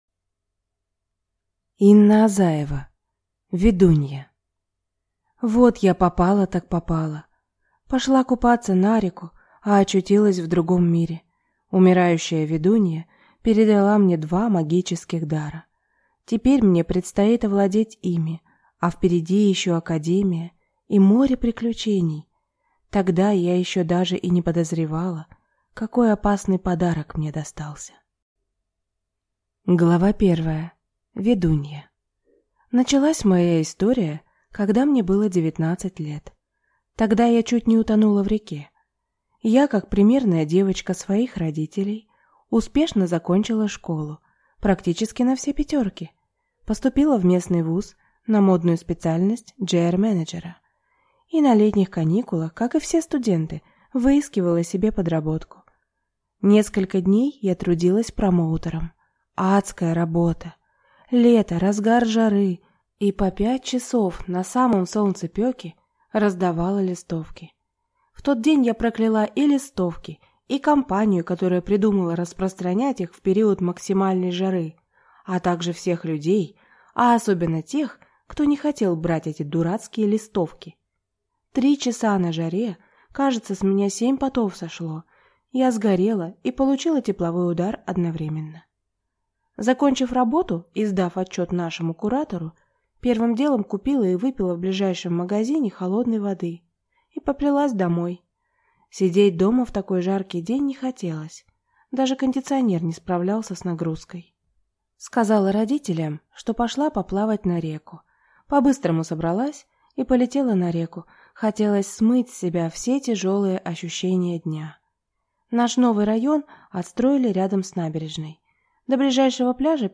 Аудиокнига Ведунья | Библиотека аудиокниг